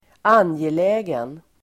Uttal: [²'an:jelä:gen]